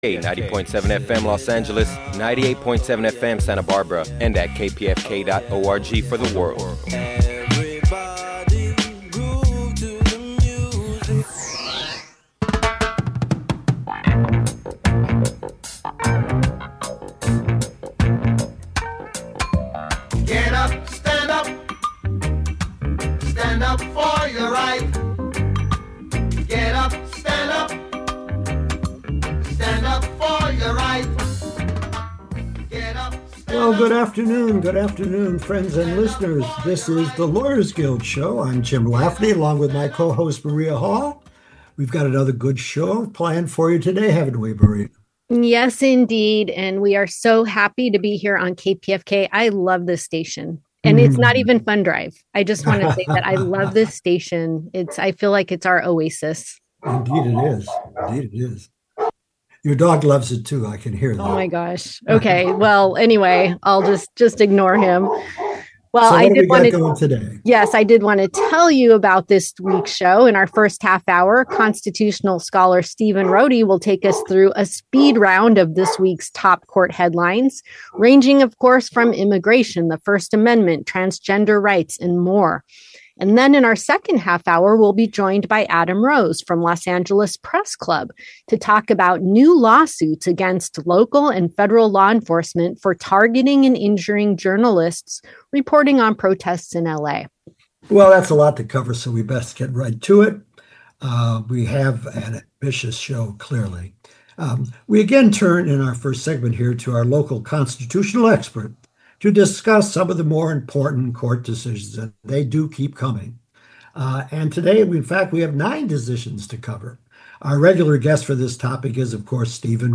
A public affairs program where political activists and experts discuss current political developments and progressive movements for social change.